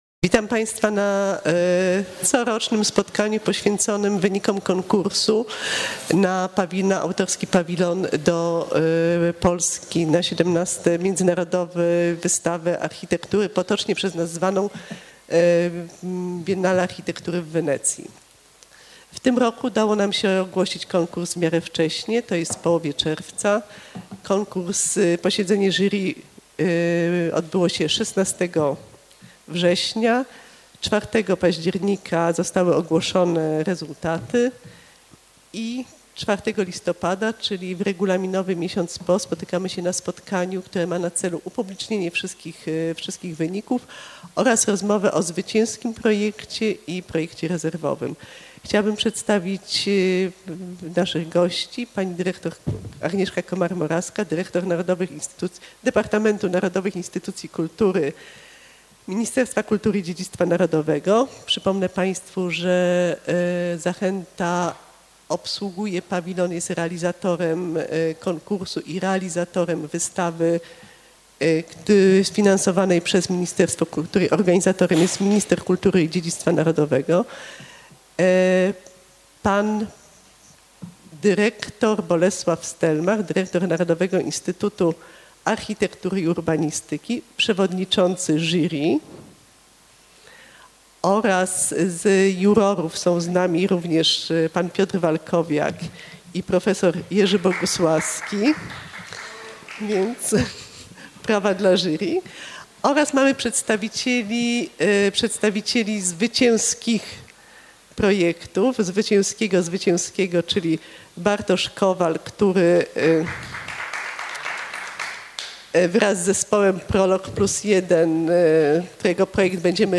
Spotkanie wokół wyników konkursu na wystawę w Pawilonie Polskim w Wenecji - Mediateka - Zachęta Narodowa Galeria Sztuki
Otwarte spotkanie poświęcone konkursowi na kuratorski projekt wystawy w Pawilonie Polskim podczas 17 Międzynarodowej Wystawy Architektury w Wenecji.